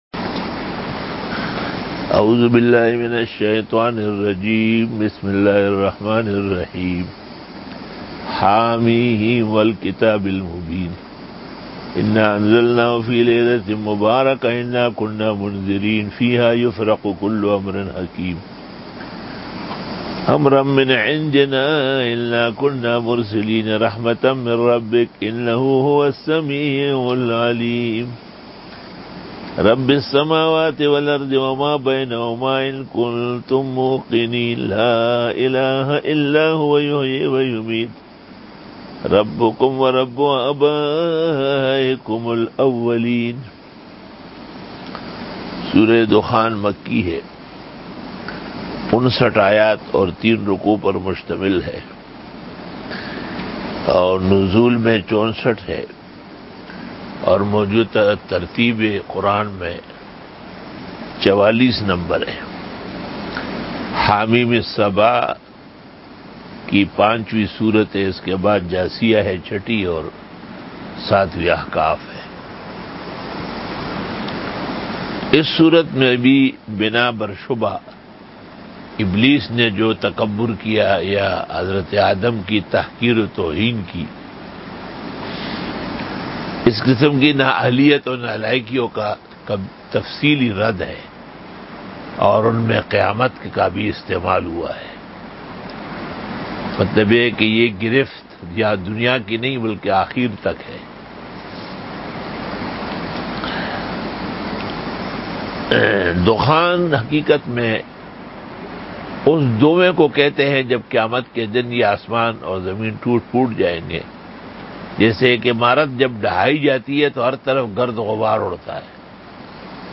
63 Quran Tafseer 15 Jun 2020 (23 Shawwal 1441 H) Monday Day 63